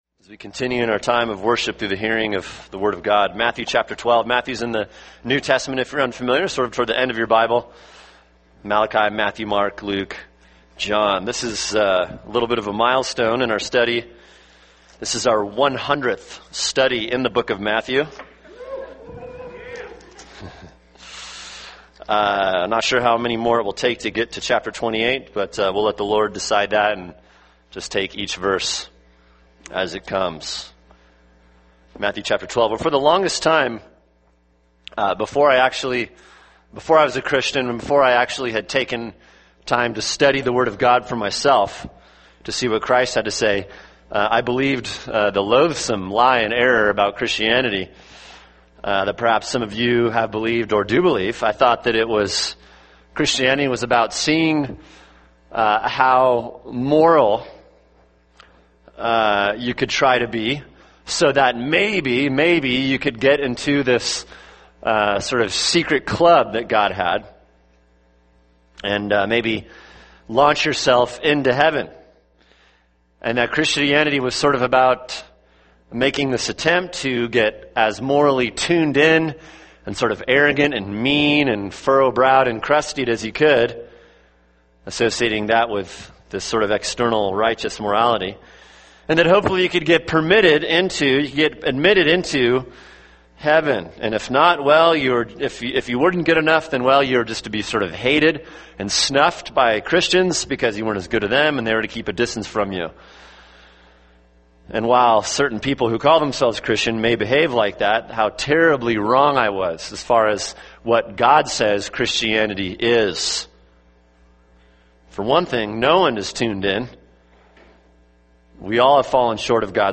[sermon] Matthew 12:18-21 – No King Like Christ (part 2) | Cornerstone Church - Jackson Hole